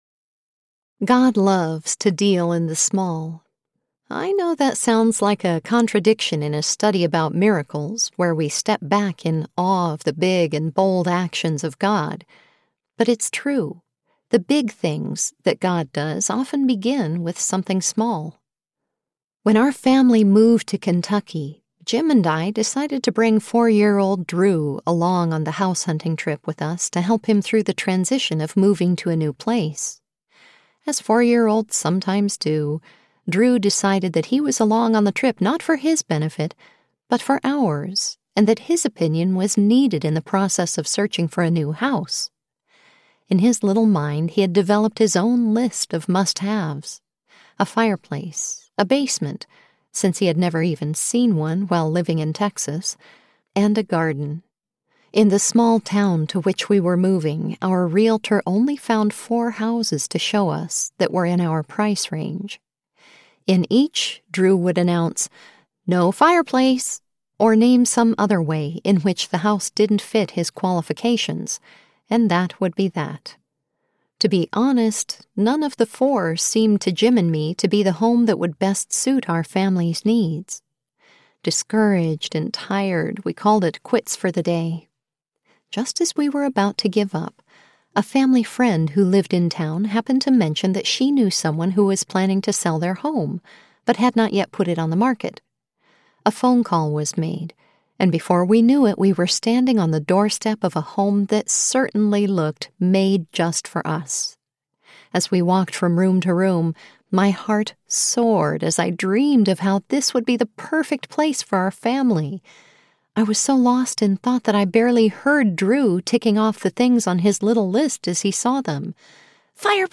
Inside the Miracles of Jesus Audiobook
5.2 Hrs. – Unabridged